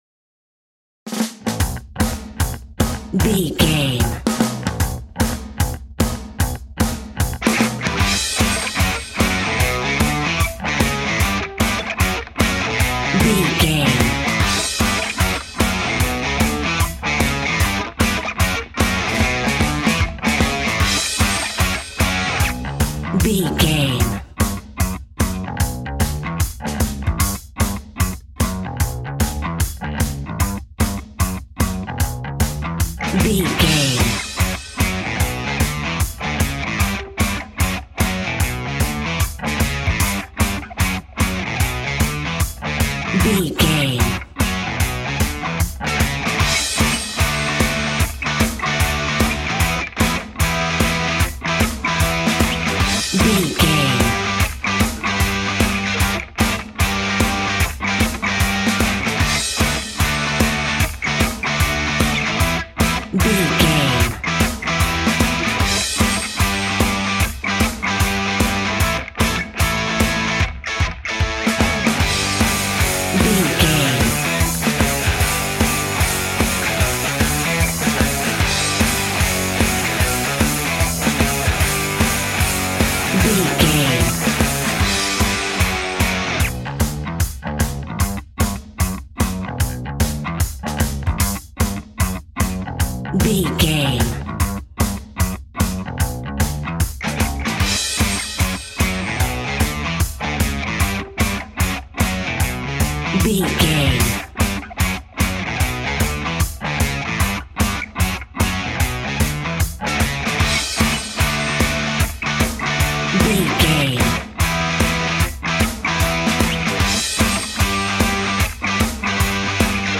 Ionian/Major
energetic
uplifting
instrumentals
upbeat
groovy
guitars
bass
drums
piano
organ